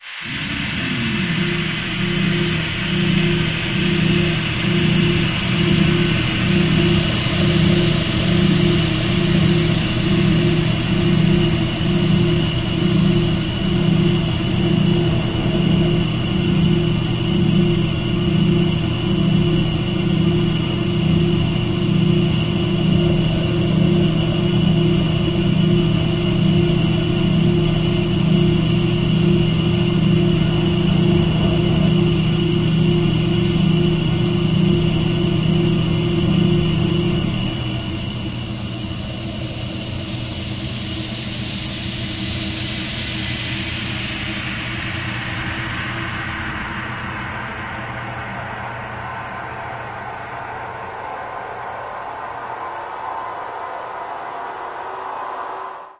Spaceship airlock pulsating tones with overlying noise filter sweeps